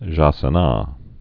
(zhäsə-nä) also ja·ca·na (-kə-)